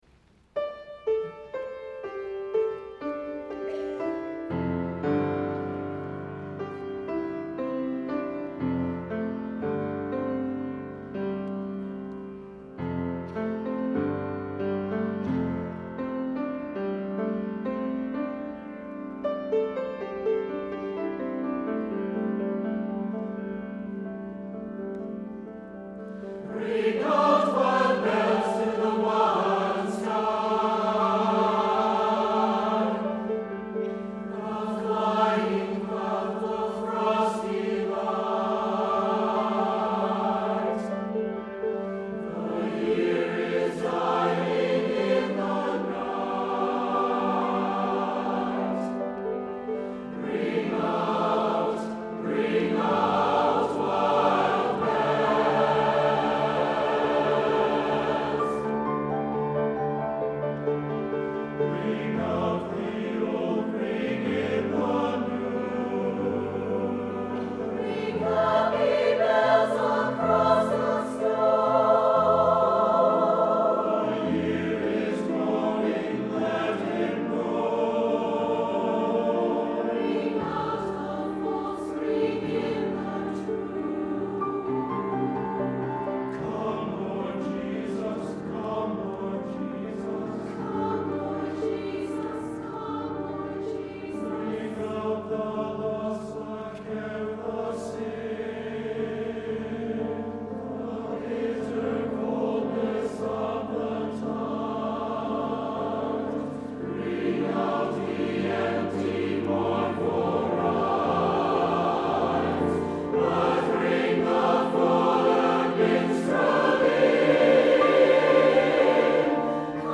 for SATB Chorus and Piano (2006)
SATB and piano